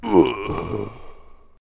sfx_enemy_dead.mp3